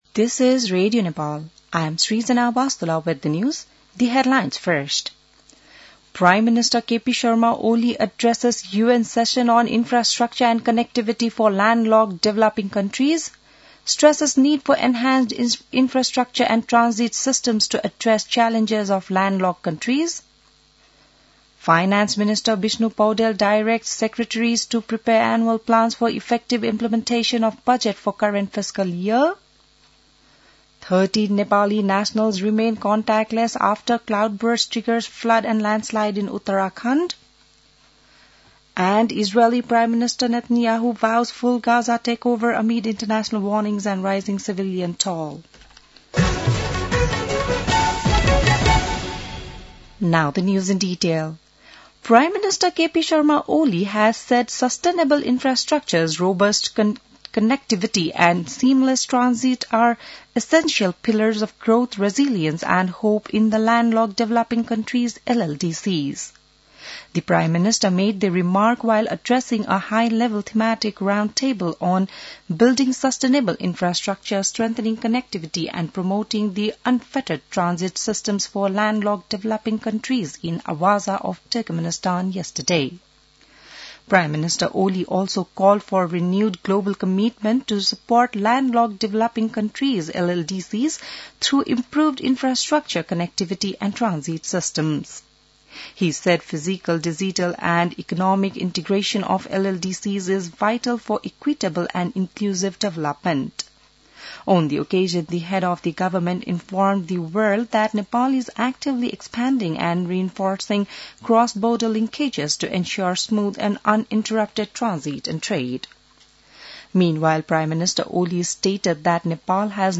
बिहान ८ बजेको अङ्ग्रेजी समाचार : २३ साउन , २०८२